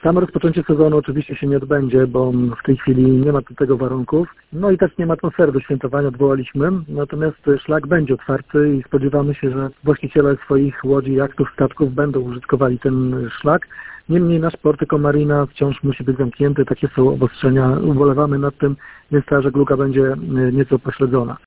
Burmistrz Giżycka Wojciech Karol Iwaszkiewicz przyznaje, że to nietypowa majówka w żeglarskiej stolicy Mazur.